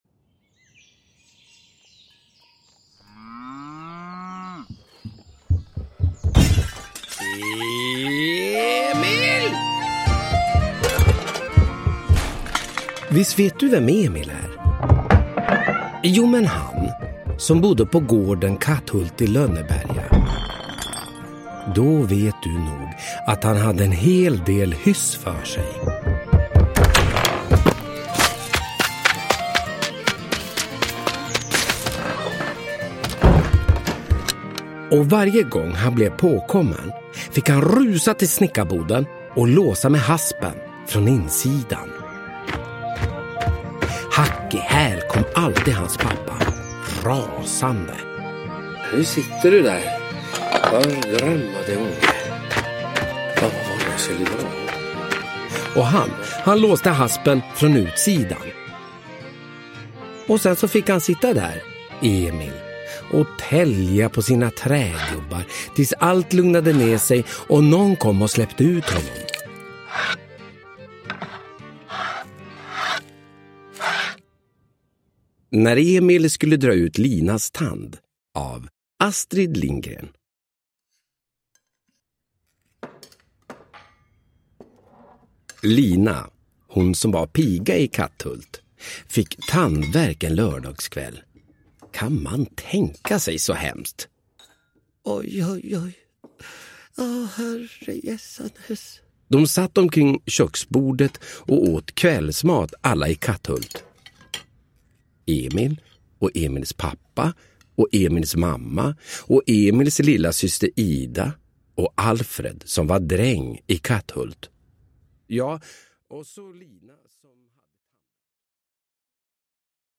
Ny inläsning av Emil i Lönneberga med stämningsfull ljudläggning!
Uppläsare: Olof Wretling